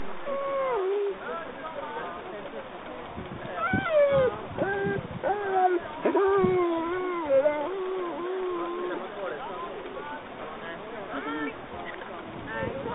Tävlingen gick av stapeln på Norrköpings Brukshundklubb.
Gnyendet i bakgrunden kommer från Chili, som inte alls ville ligga stilla hos husse när Kenzo och jag var inne på planen...